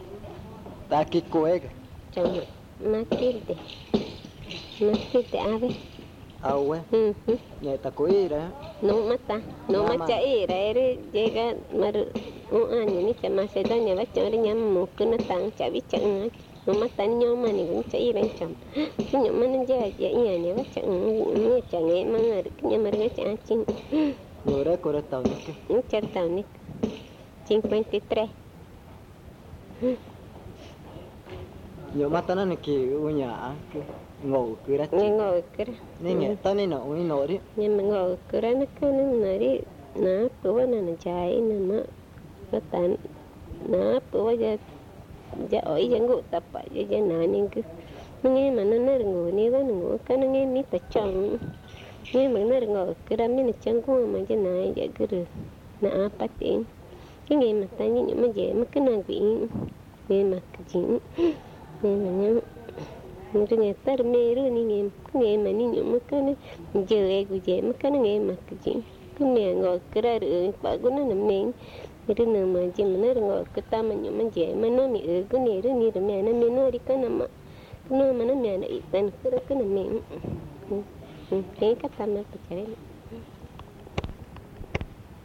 Pozo Redondo, Amazonas (Colombia)